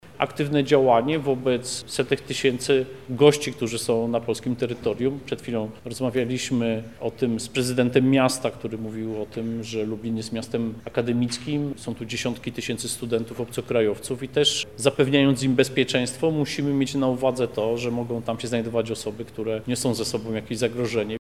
Tomasz Siemoniak– dodaje polityk.